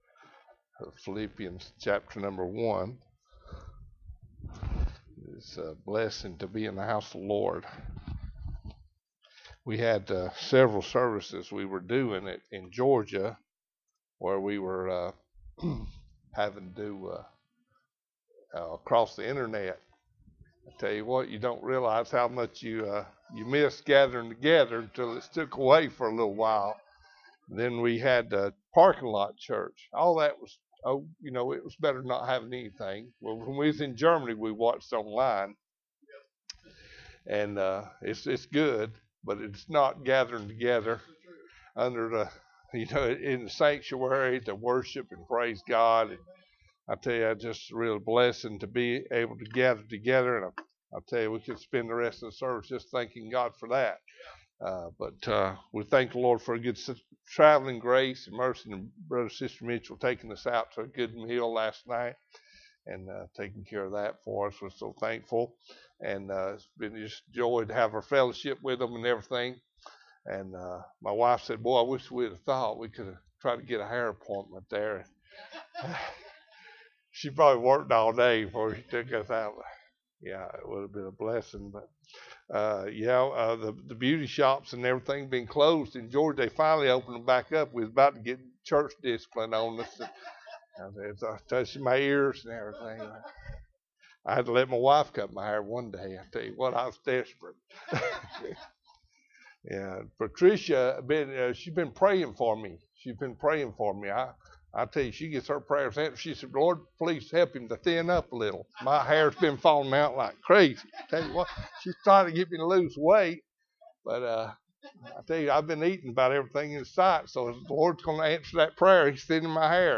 Philippians 1:20 Service Type: Sunday Morning Bible Text